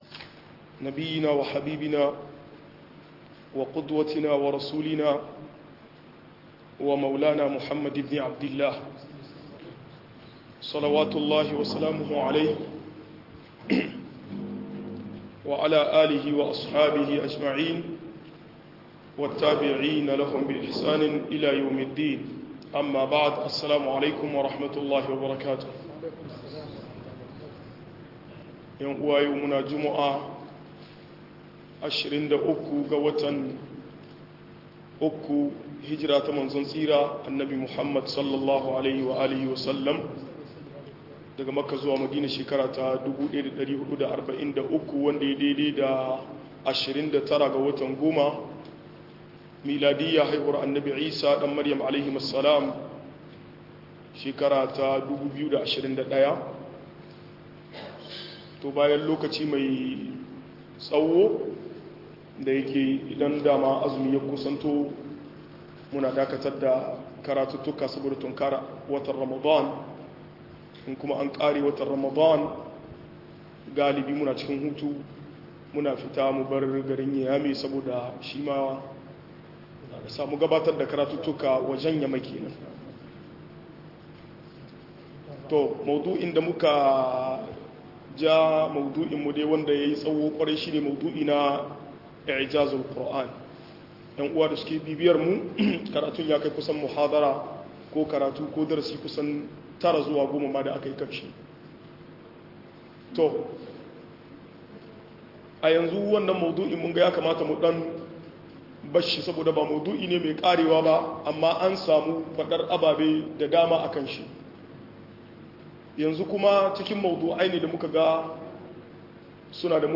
Cututtuka da ke bata tarbiyya - MUHADARA